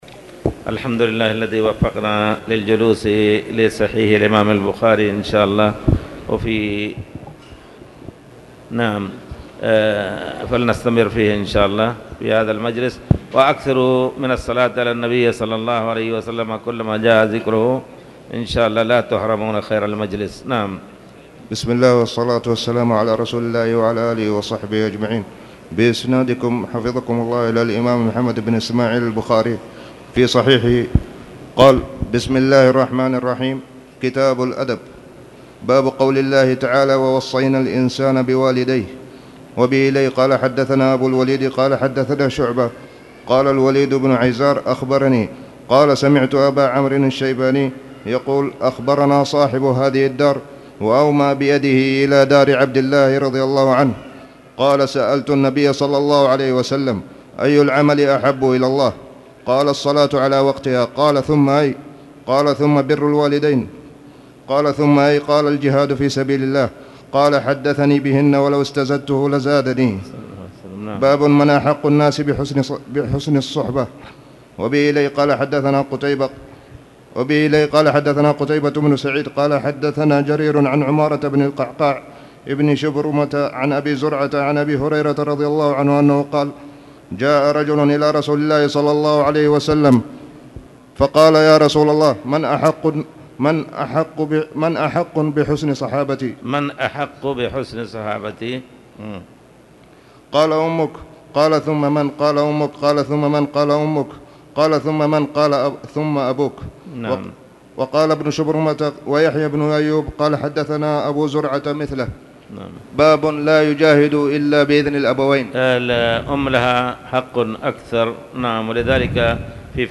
تاريخ النشر ٢ ربيع الثاني ١٤٣٨ هـ المكان: المسجد الحرام الشيخ